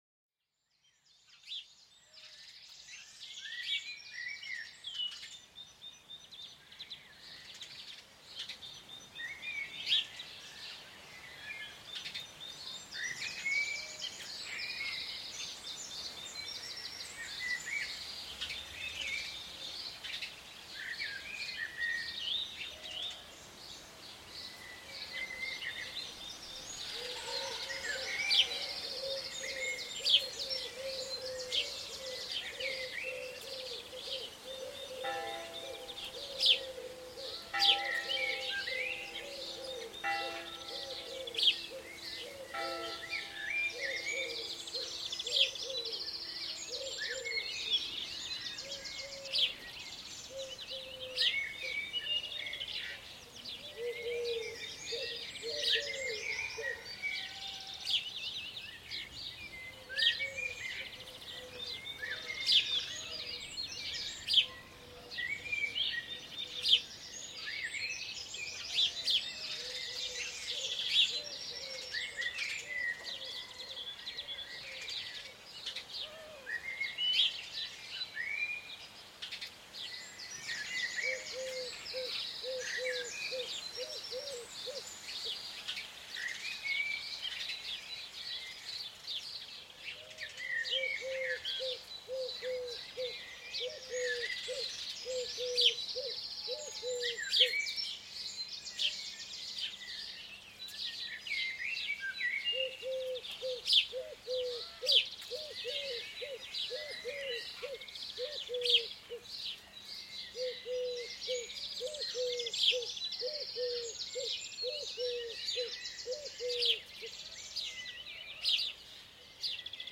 Plongez au cœur des champs avec cet épisode qui capte l'essence des vastes étendues rurales. Laissez-vous transporter par le murmure du vent et le frémissement des herbes sous la caresse du soleil couchant.